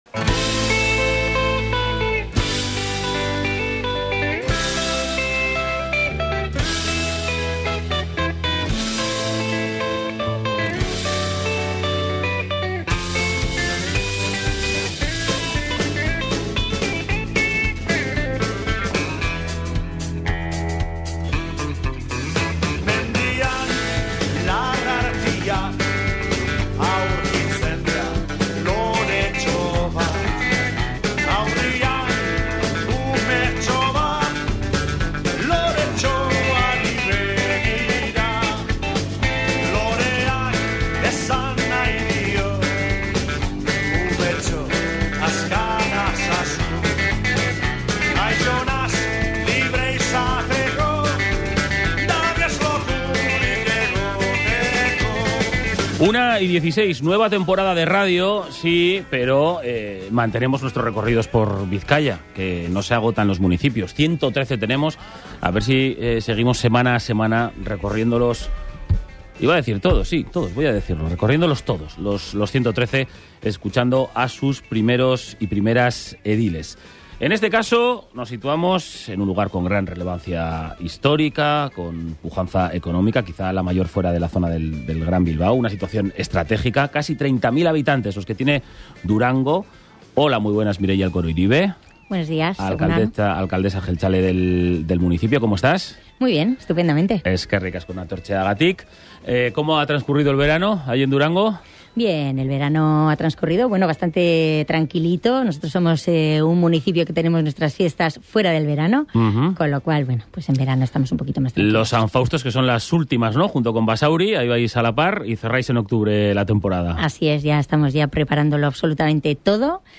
La alcaldesa, Mireia Elkoroiribe, conversa con nosotras sobre los proyectos clave que se han ejecutado hasta esta mitad de legislatura y los desafíos que aún quedan por afrontar.